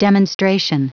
Prononciation du mot demonstration en anglais (fichier audio)